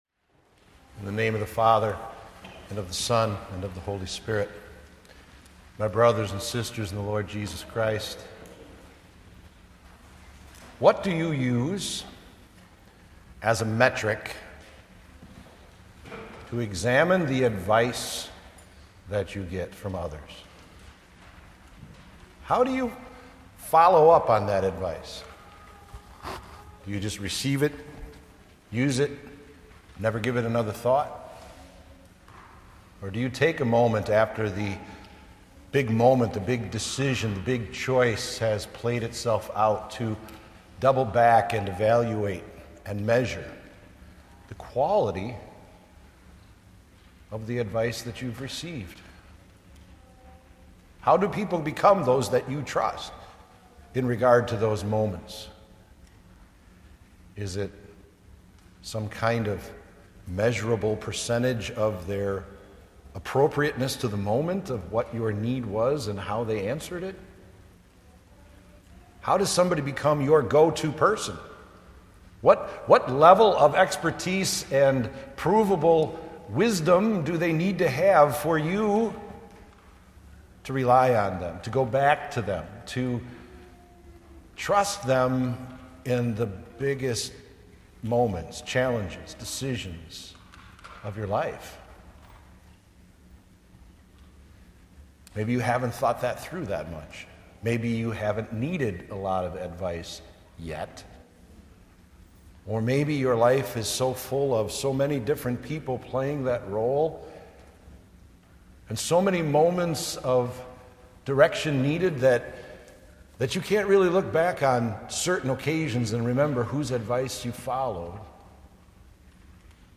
Sermons from Faith Evangelical Lutheran Church (WELS) in Antioch, IL